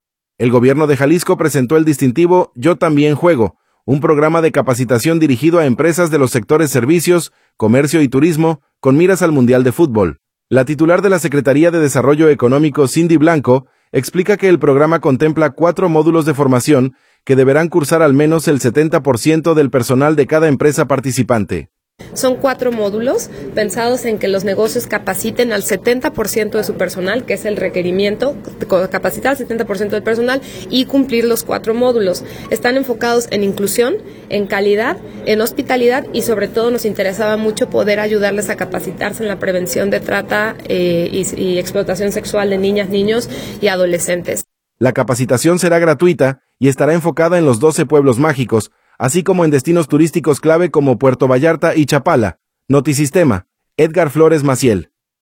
El Gobierno de Jalisco presentó el distintivo “Yo también juego”, un programa de capacitación dirigido a empresas de los sectores servicios, comercio y turismo, con miras al Mundial de Futbol. La titular de la Secretaría de Desarrollo Económico, Cindy Blanco, explica que el programa contempla cuatro módulos de formación que deberán cursar al menos el 70 por ciento del personal de cada empresa participante.